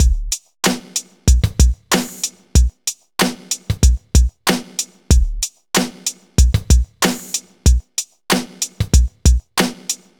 Ridin_ Dubs - Beat 01.wav